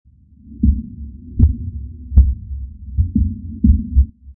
Download Giant Footsteps sound effect for free.
Giant Footsteps